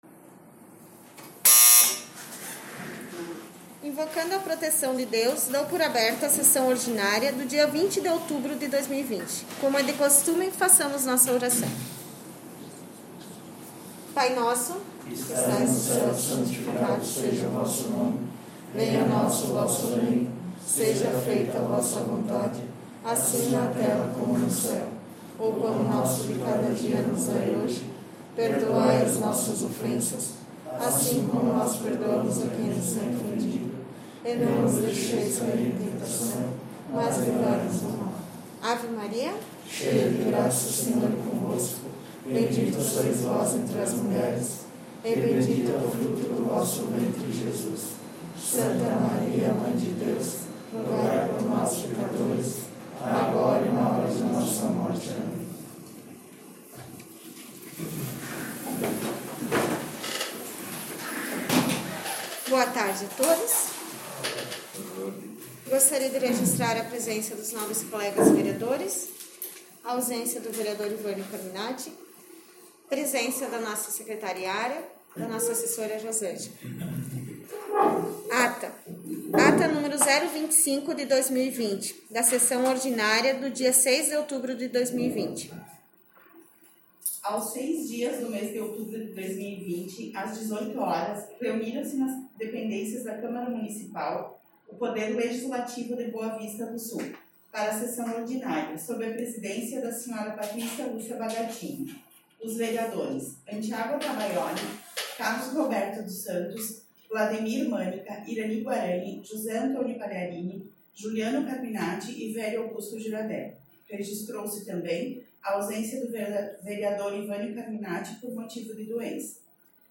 Sessão Ordinária 20/10/2020